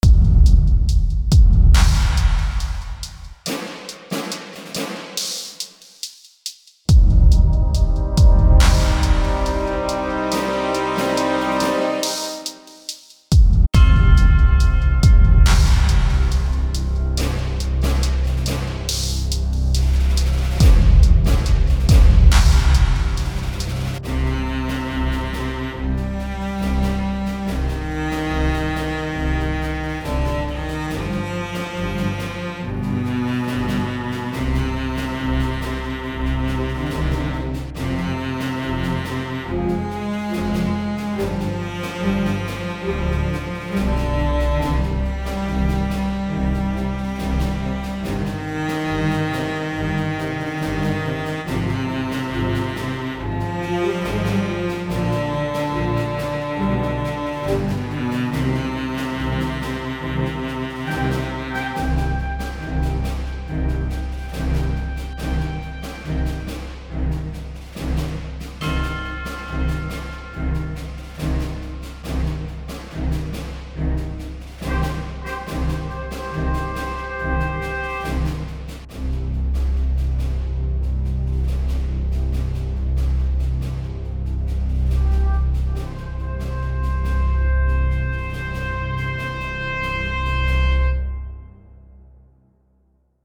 Le thème de violoncelle qui suit, très militaire, mais emprunt de tristesse, représente bien la défaite telle qu'énoncée dans le titre de la pièce, mais je me serais attendu à quelque chose ramenant plus de sentiments différents aussi.